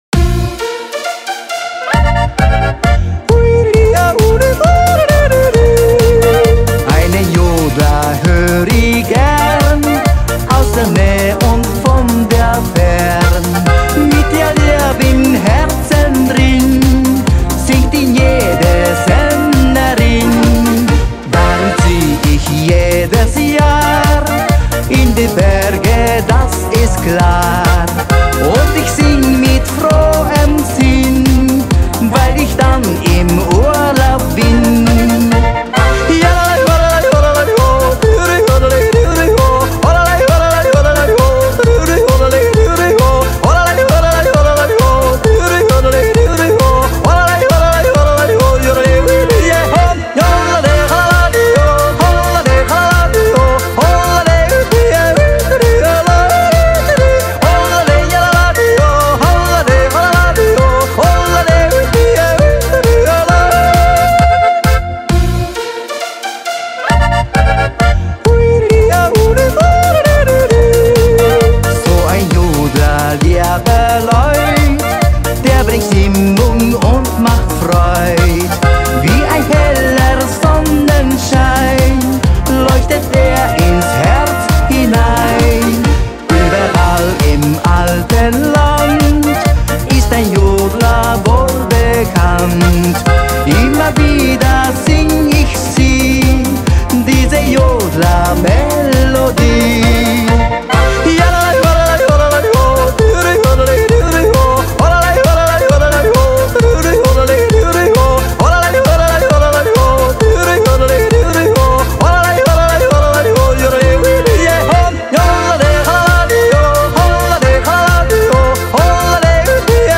BPM133
Comments[YODEL]